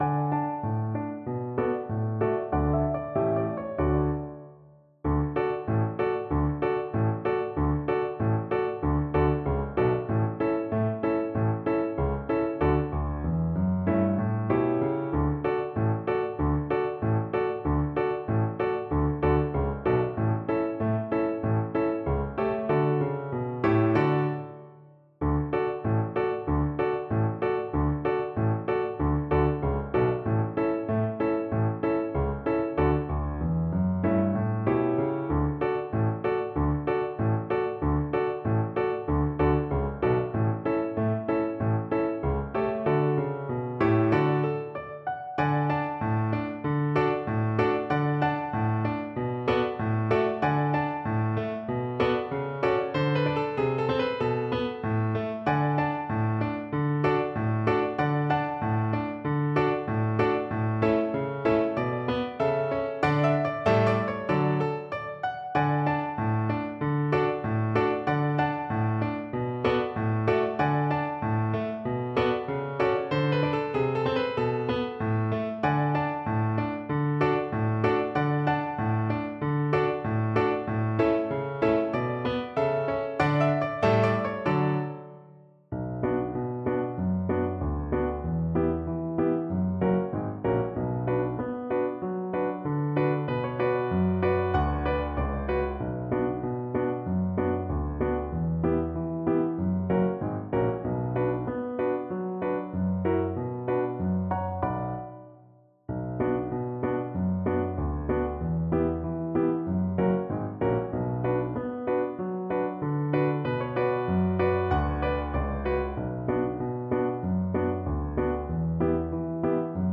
Cello
Traditional Music of unknown author.
D major (Sounding Pitch) (View more D major Music for Cello )
Allegro =c.140 (View more music marked Allegro)
2/4 (View more 2/4 Music)